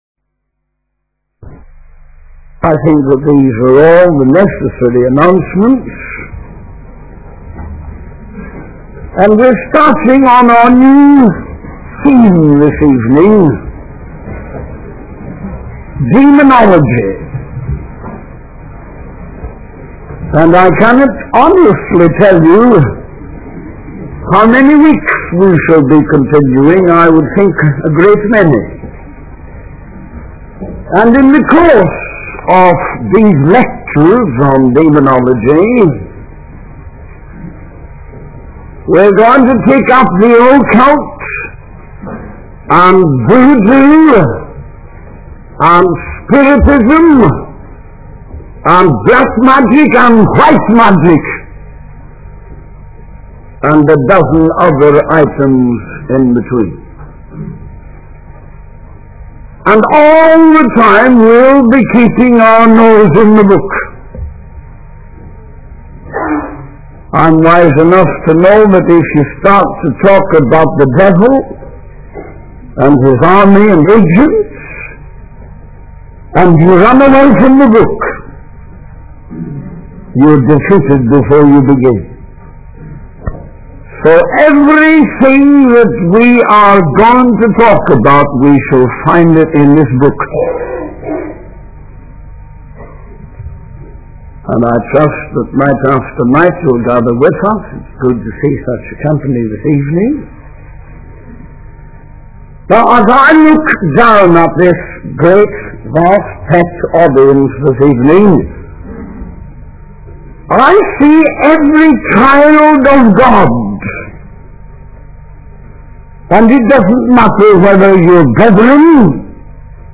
In this sermon, the preacher discusses the concept of angels and their rebellion against God. He mentions that some angels were cast down to hell and reserved for judgment because they went too far in their rebellion.